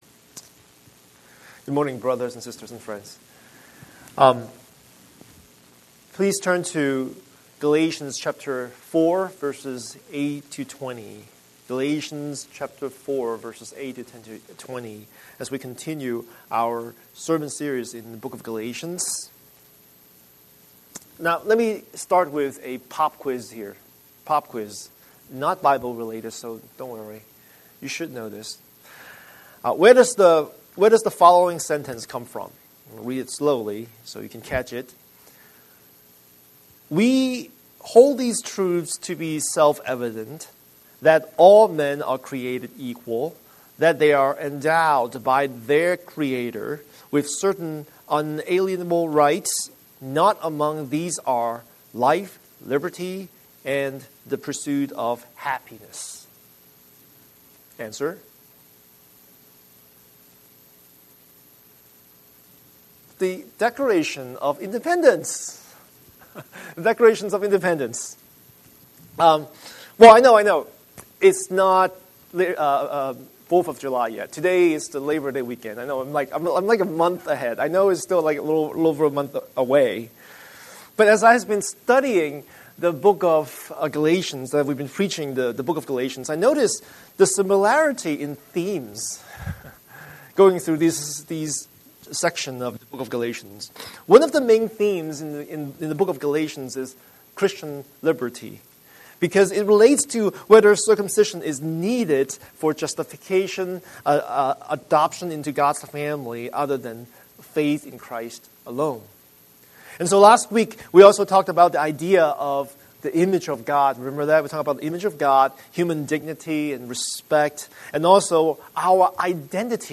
Scripture: Galatians 4:8-20 Series: Sunday Sermon